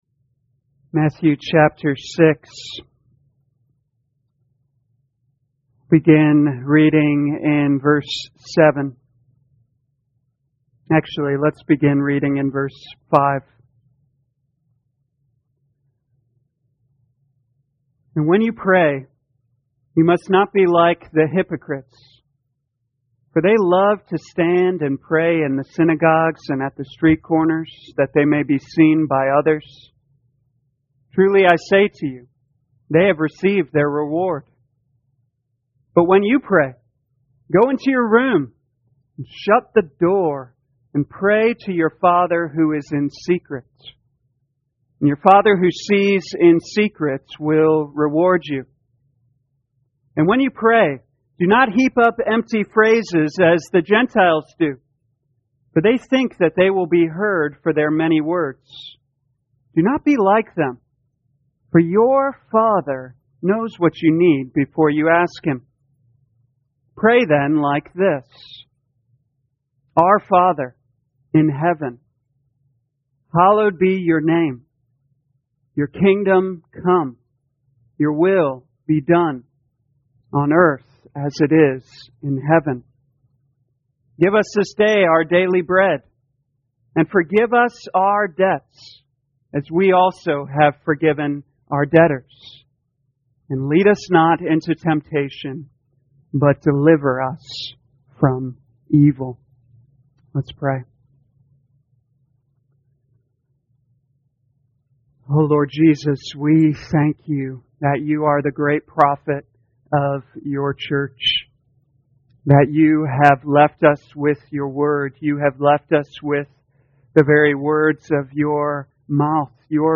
2021 Matthew Prayer Evening Service Download